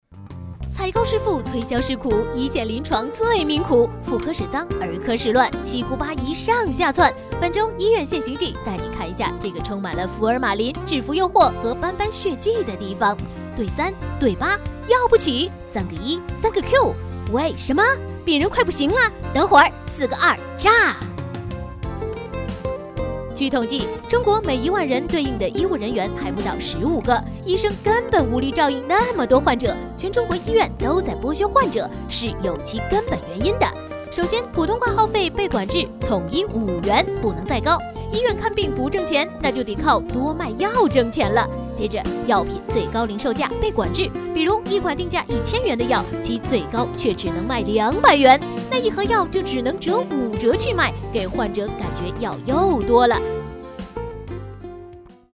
【飞碟说】飞碟说女8-轻快
【飞碟说】飞碟说女8-轻快.mp3